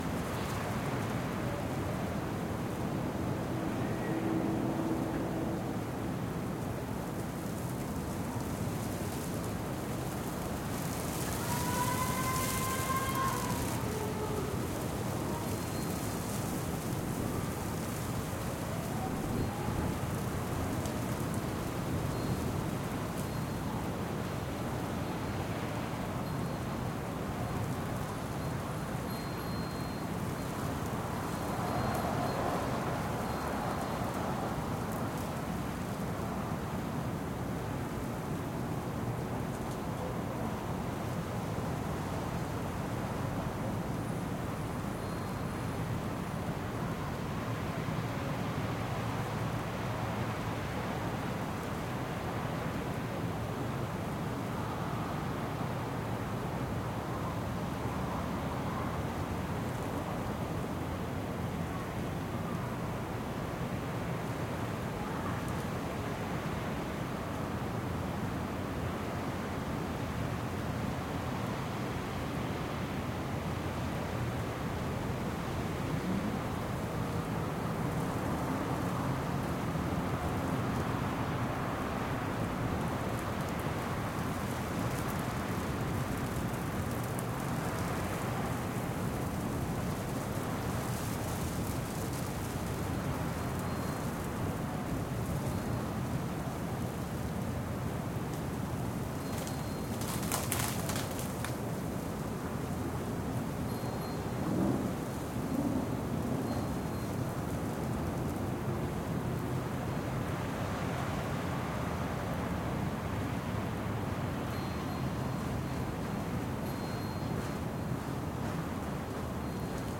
Winter night - frozen trees Sound Effect — Free Download | Funny Sound Effects
Ambience of a night in December. It snowed earlier in the day, with freezing rain after.